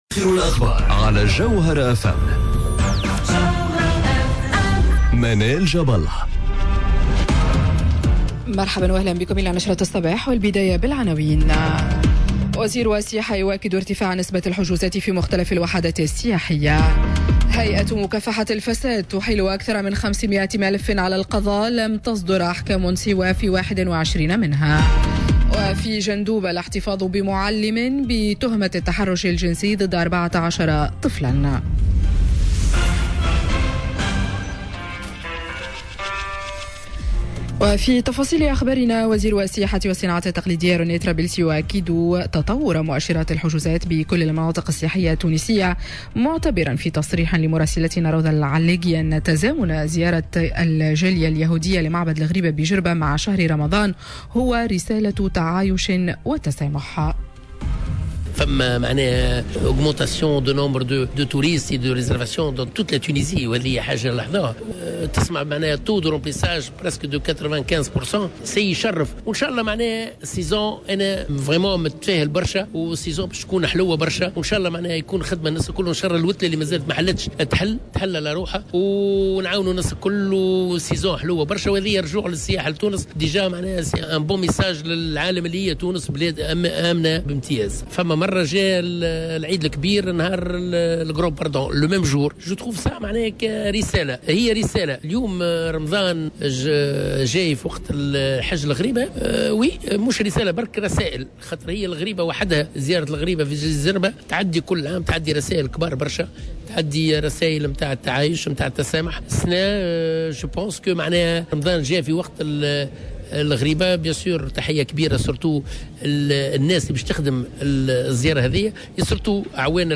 نشرة أخبار السابعة صباحا ليوم الثلاثاء 30 أفريل 2019